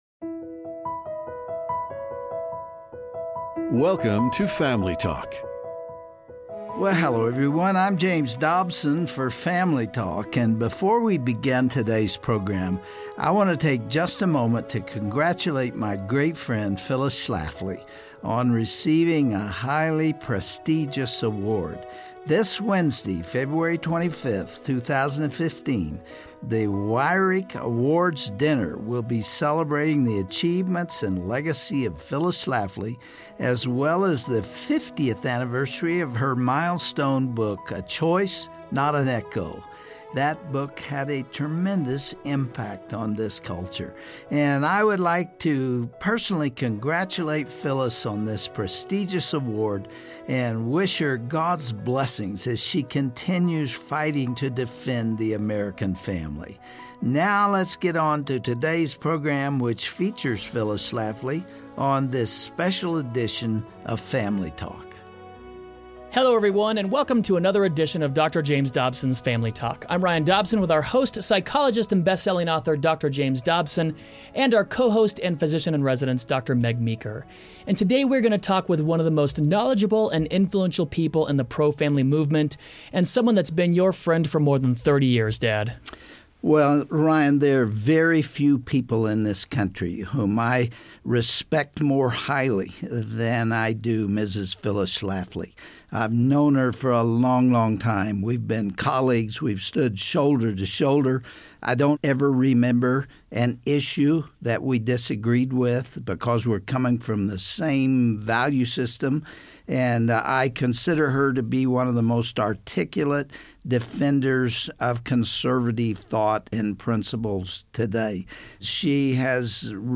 Dr. Dobson interviews Phyllis Schlafly about the state of the American Family today.